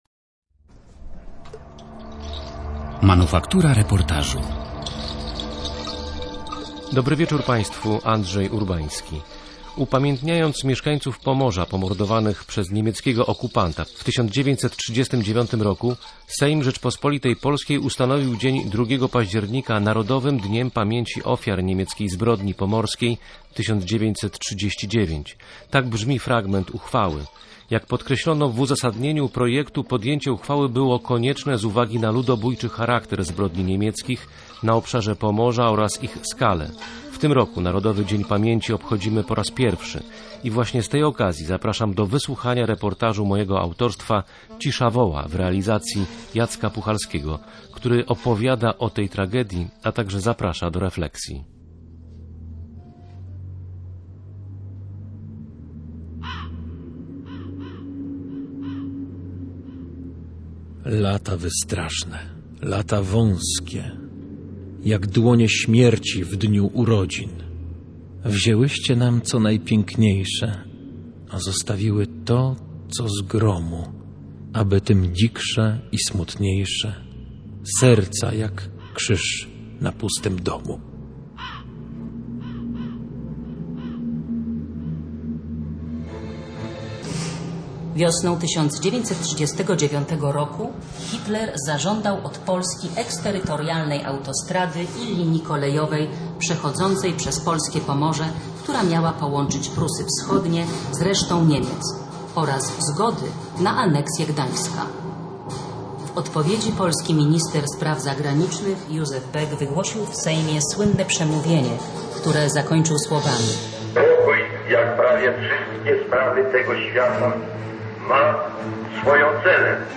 Największą niemiecką akcję eksterminacyjną przeprowadzono na Pomorzu. Reportaż „Cisza woła”
Z tej okazji w audycji „Manufaktura Reportażu” prezentujemy reportaż o tej zbrodni.